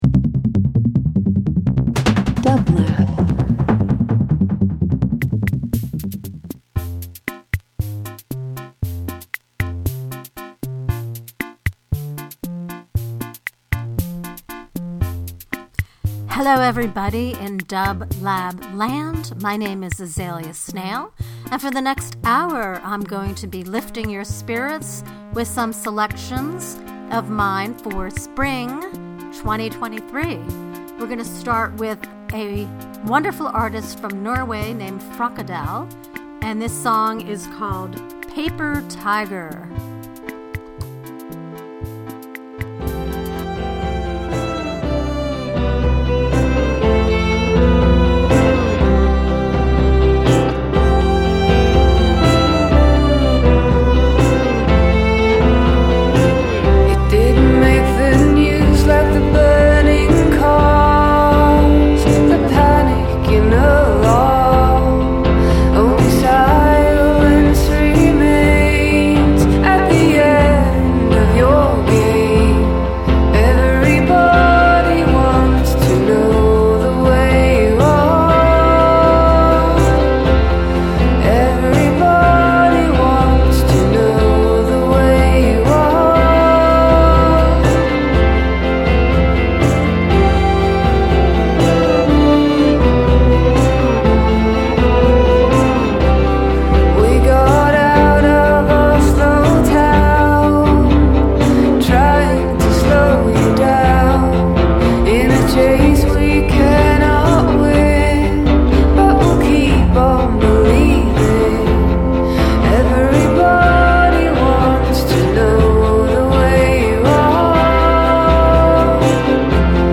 Pop Psychedelic Rock Rock